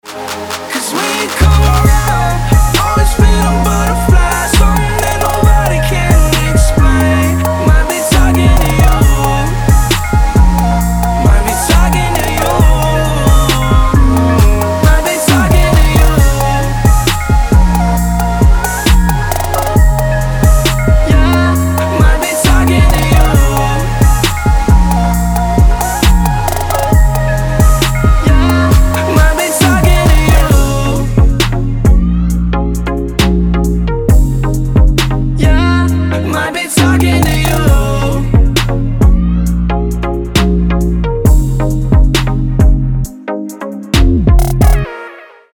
мужской вокал
красивые
Хип-хоп
Bass